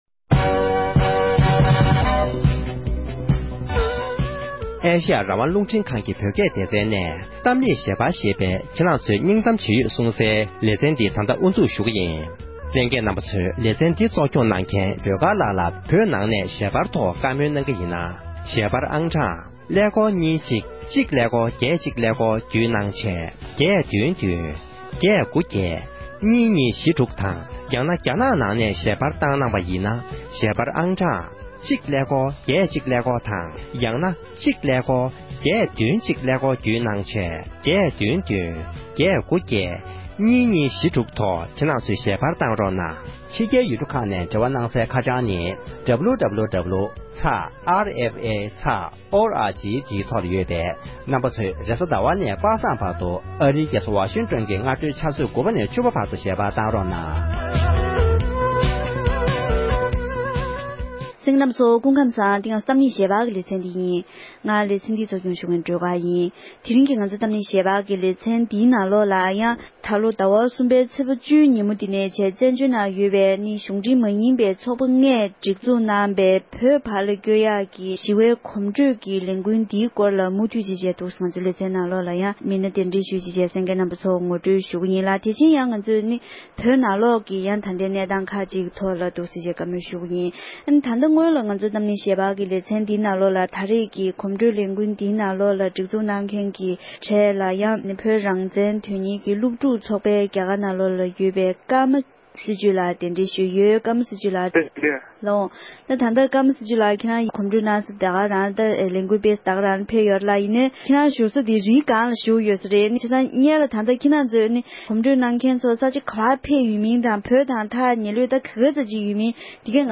གཞུང་འབྲེལ་མ་ཡིན་པའི་ཚོགས་ལྔས་གོ་སྒྲིག་གནང་བའི་ཞི་བའི་གོམ་བགྲོད་ཀྱི་ལས་འགུལ་དང་བོད་ནང་གི་གནས་སྟངས་ཐོག་གི་བགྲོ་གླེང༌།